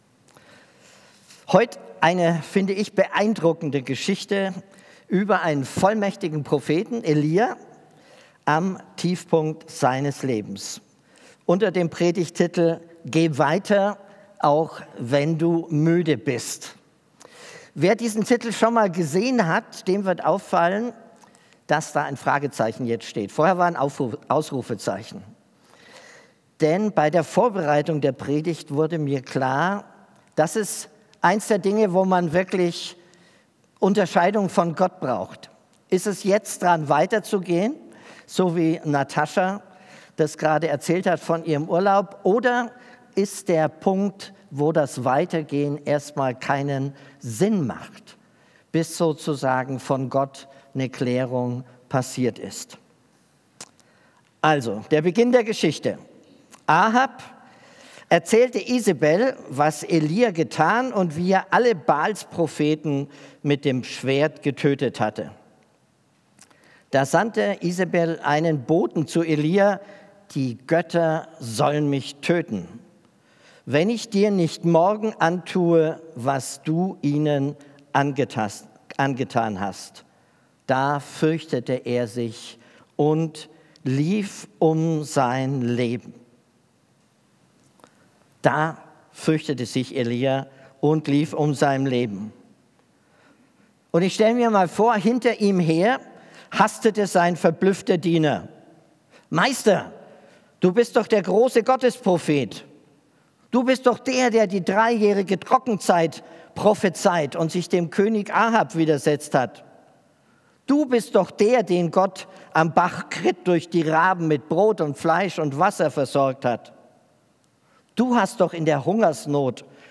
Predigt Geh weiter, auch wenn du müde bist 1.